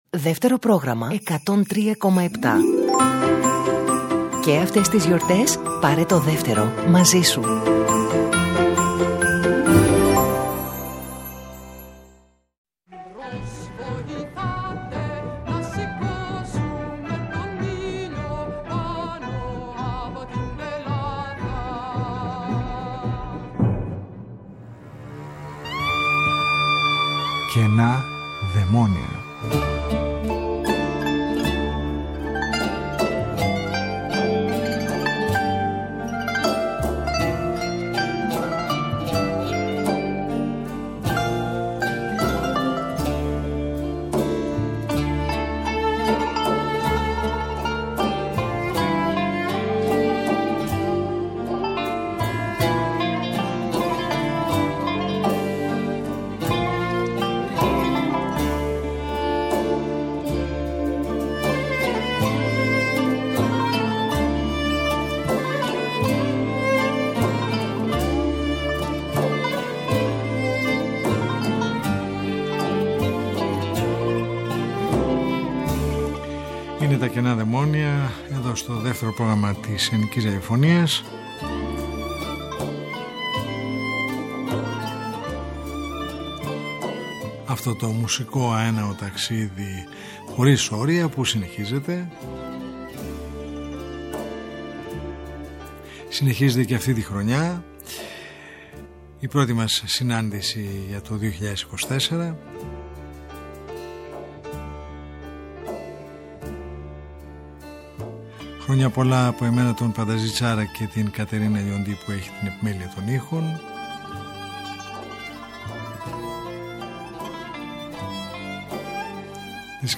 σας προσκαλούν σ’ ένα μουσικό οδοιπορικό με ήχους κυρίως της Ελληνικής παράδοσης διανθισμένους από τις πολύ γνωστές και αγαπημένες μελωδίες από τα κάλαντα των ” Φώτων ”.
Καινά Δαιμόνια : Μια ραδιοφωνική συνάντηση κάθε Σάββατο στις 22:00 που μας οδηγεί μέσα από τους ήχους της ελληνικής δισκογραφίας του χθες και του σήμερα σε ένα αέναο μουσικό ταξίδι.